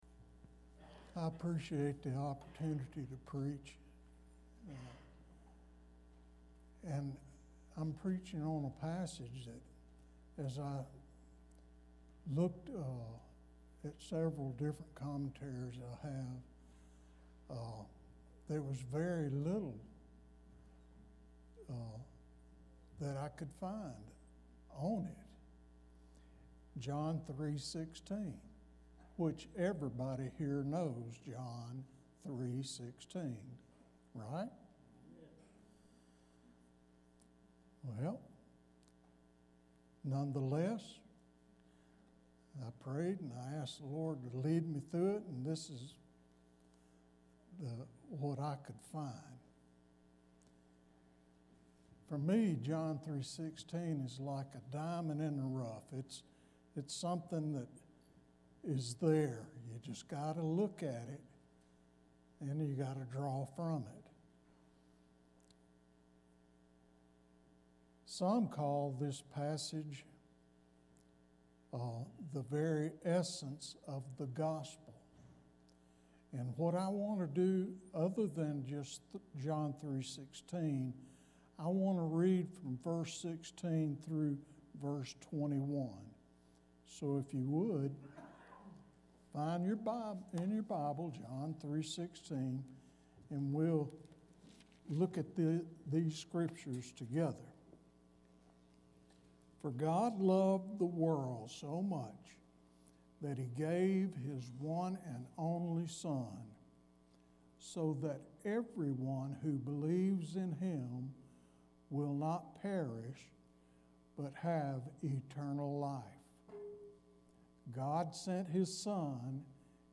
Service Type: Sermon only